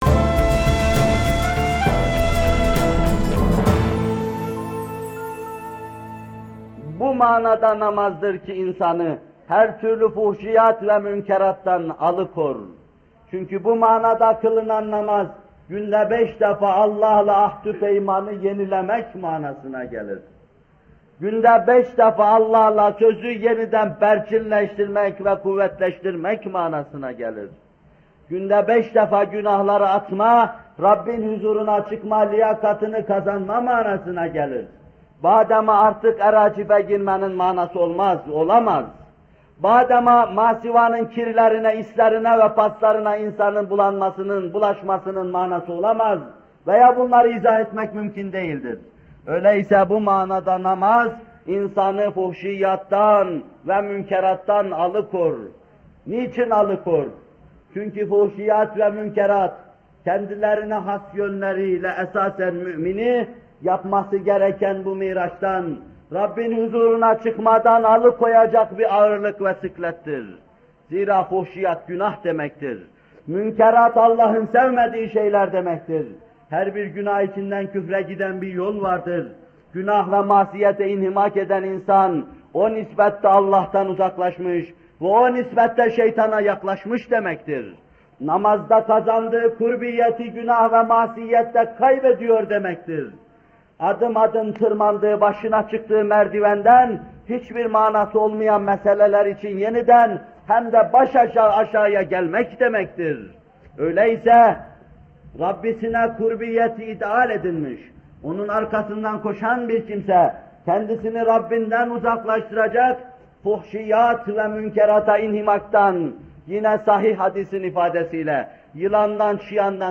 Bu bölüm Muhterem Fethullah Gülen Hocaefendi’nin 8 Eylül 1978 tarihinde Bornova/İZMİR’de vermiş olduğu “Namaz Vaazları 4” isimli vaazından alınmıştır.